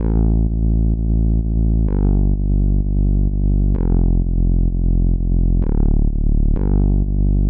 VDE 128BPM Notice Bass 1 Root A SC.wav